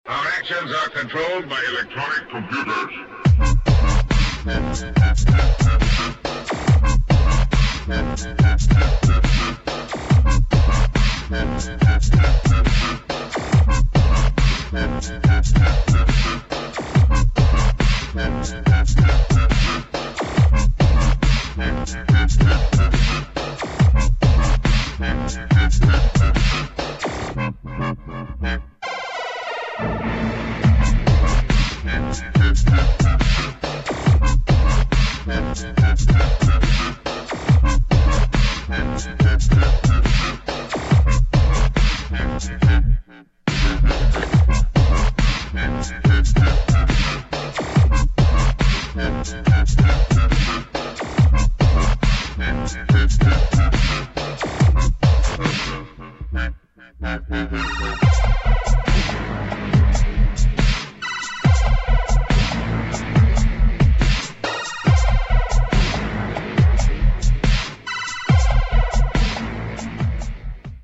[ DUBSTEP / UK GARAGE / BREAKS ]